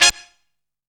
GENTLE HIT.wav